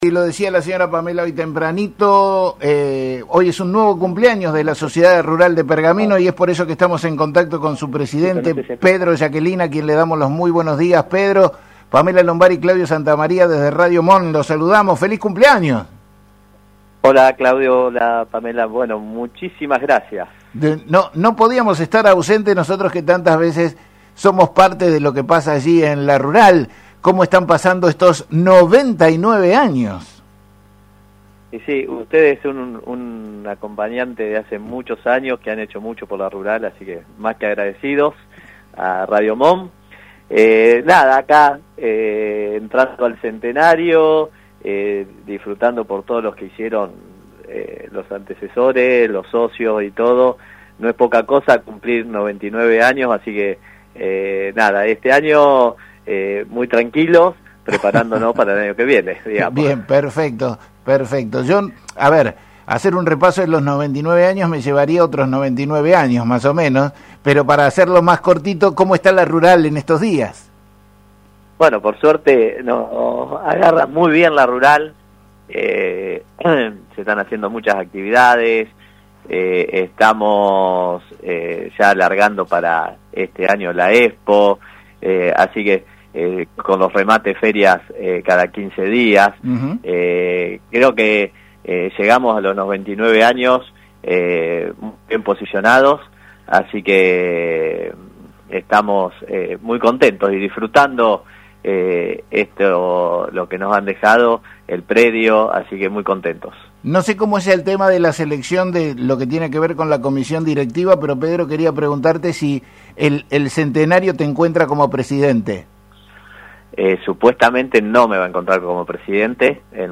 En la entrevista